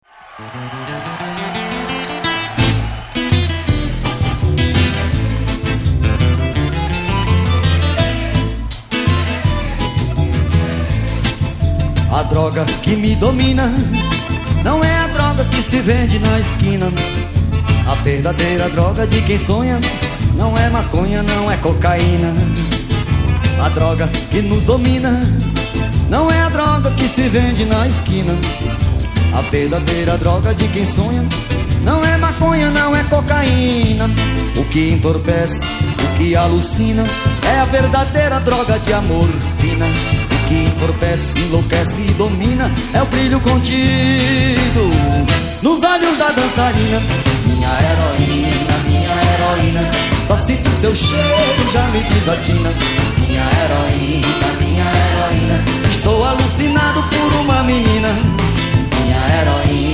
(Ao vivo)